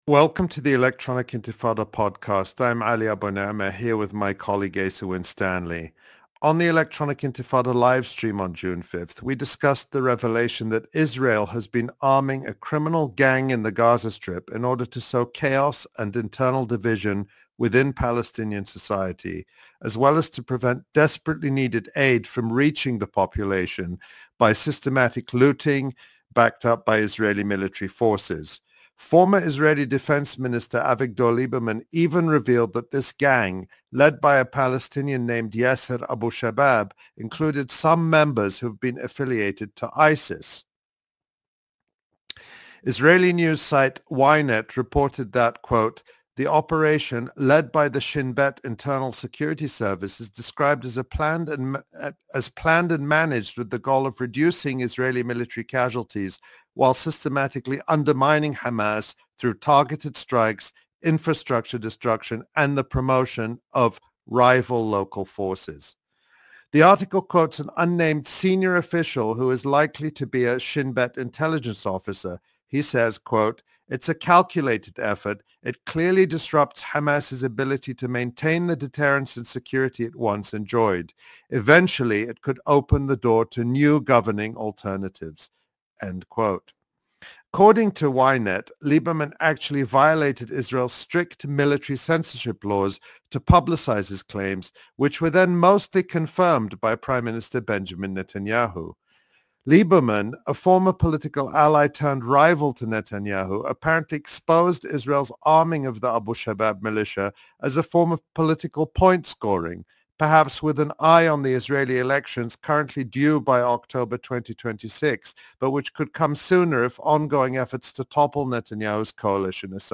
A Palestinian analyst and writer from Gaza